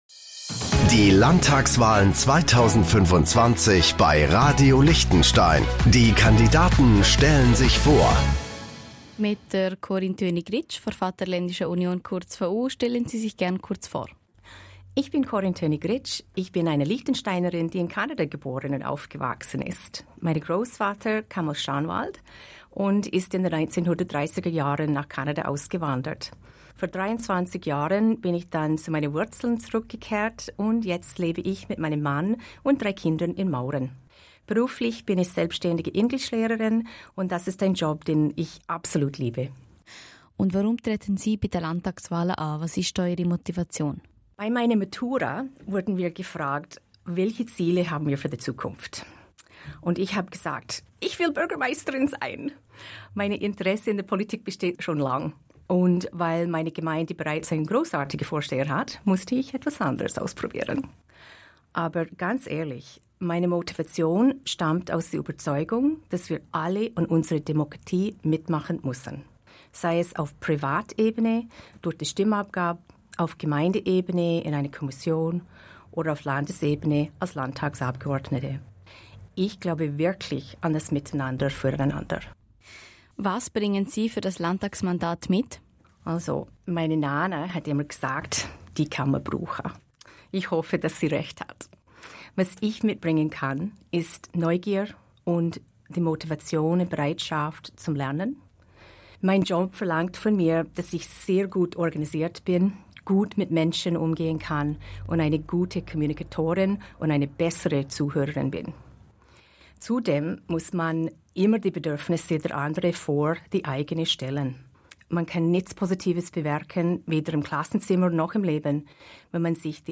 Landtagskandidatin